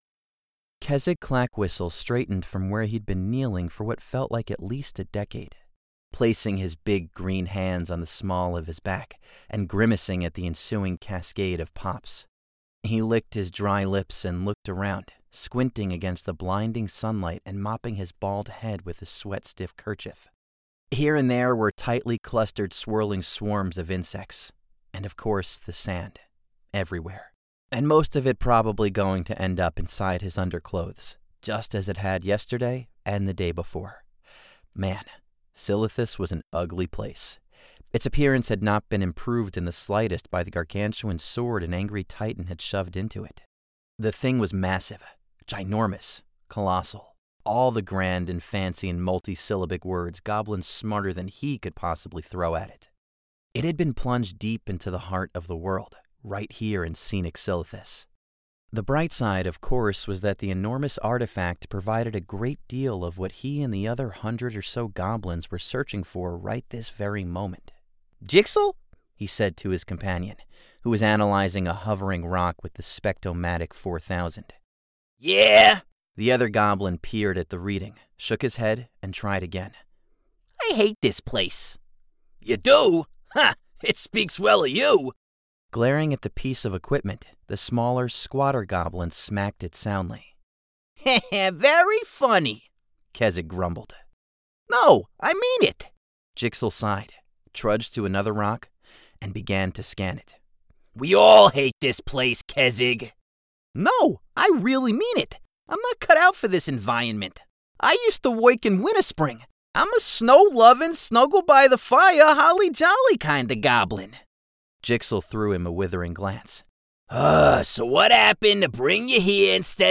Male
Assured, Authoritative, Bright, Bubbly, Character, Confident, Cool, Corporate, Engaging, Friendly, Gravitas, Natural, Reassuring, Sarcastic, Smooth, Soft, Streetwise, Wacky, Warm, Witty, Versatile, Young
His voice has been described as youthful, modern, real, genuine, conversational, millennial, authentic, bright, warm, enthusiastic, hip, cool, sincere, and fun.
Microphone: Neumann TLM 103
Audio equipment: SSL2 Interface and Whisper Room Booth